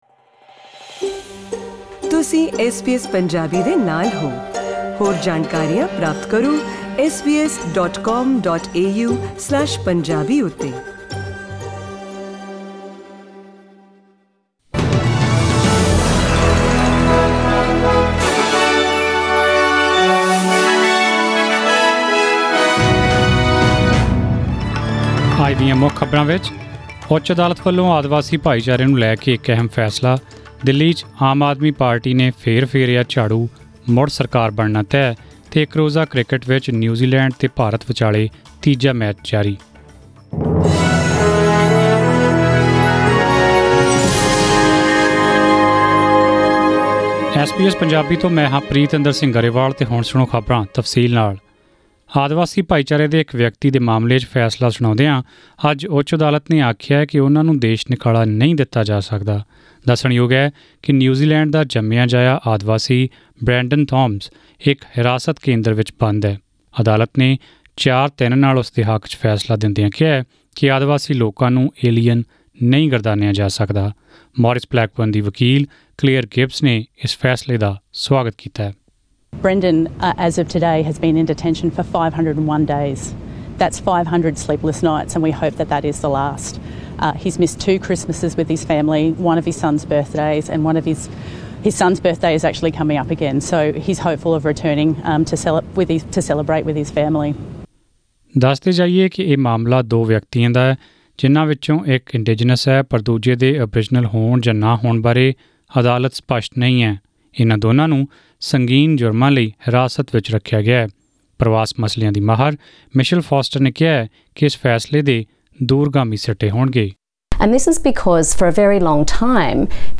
Australian News in Punjabi: 11 February 2020